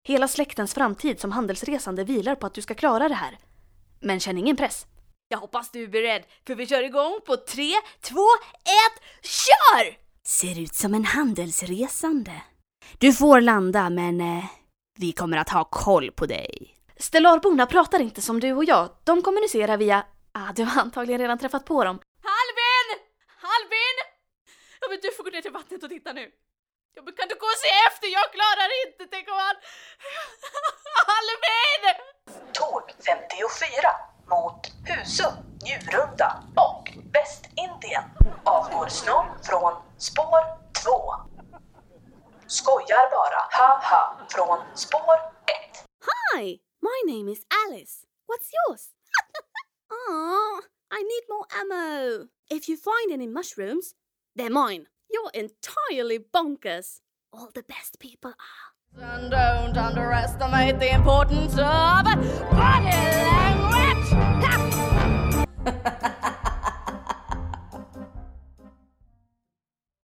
Female
Character, Confident, Natural, Warm, Witty, Versatile, Approachable, Conversational, Corporate, Energetic, Funny, Young
Northern Swedish (native). General Swedish.
Audiobook.mp3
Microphone: Neumann TLM 103
Audio equipment: Voice booth